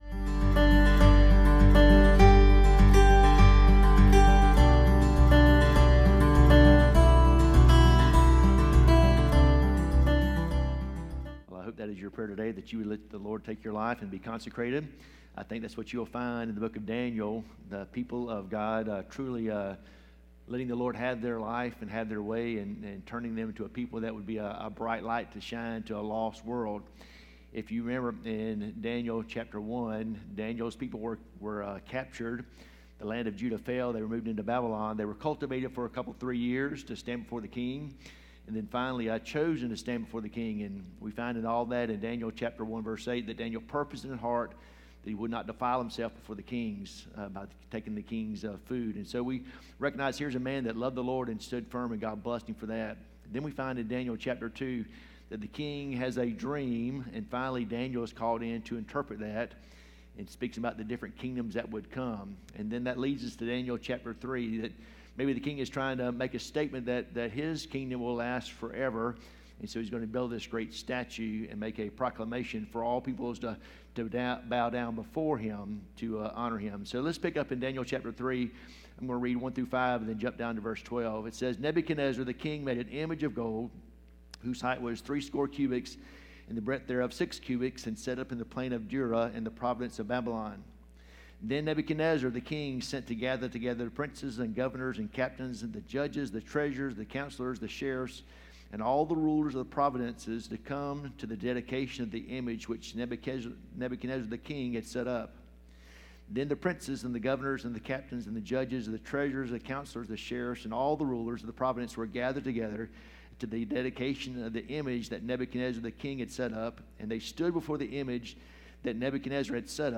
Sermons 2 – Sardis Baptist Church | Worthington Springs, FL